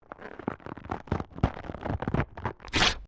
Slow to fast Rip
SFX
yt_AsTSK7RABHk_slow_to_fast_rip.mp3